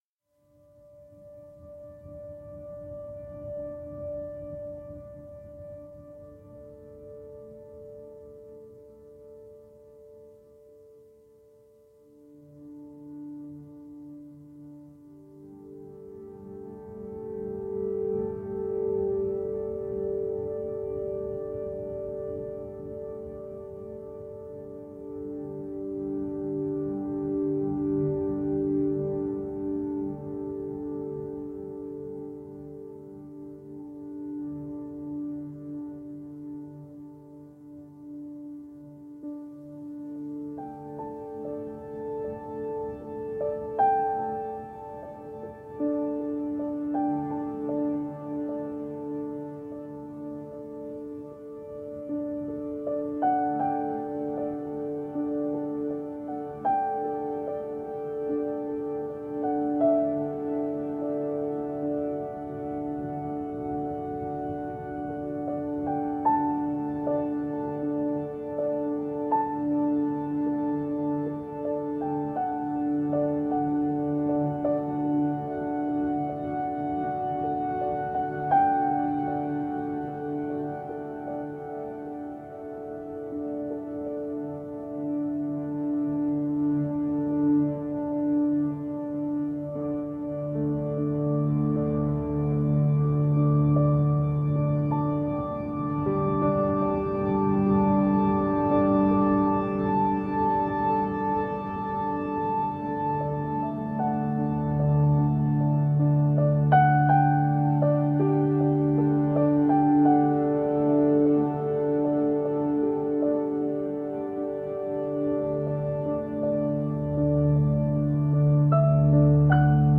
Minimalist piano with slashes of ambient electronics.